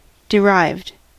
Ääntäminen
Ääntäminen US UK : IPA : /dɪˈɹaɪvd/ Haettu sana löytyi näillä lähdekielillä: englanti Käännös Adjektiivit 1. johdettu Derived on sanan derive partisiipin perfekti.